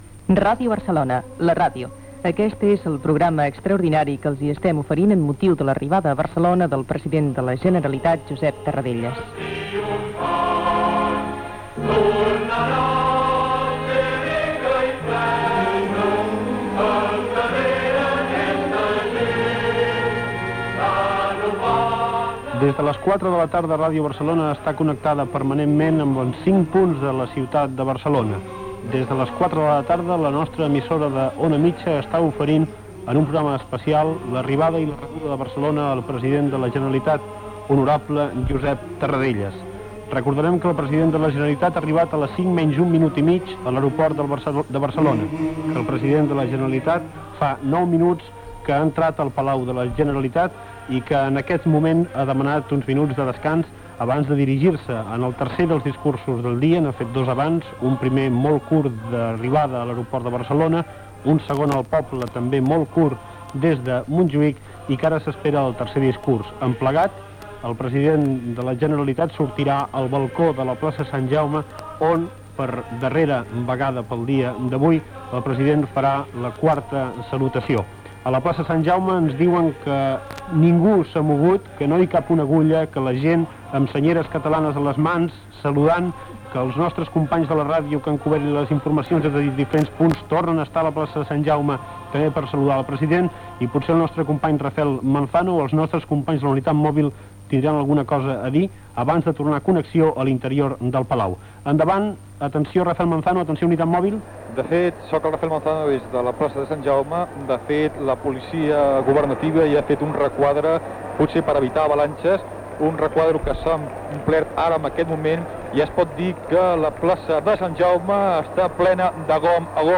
Transmissió del retorn de l'exili del president de la Generalitat Josep Tarradellas a la ciutat de Barcelona.
Ambient a la Plaça de Sant Jaume i al Palau de la Generalitat. Entrada de Tarradellas al Saló de Sant Jordi i discurs del president.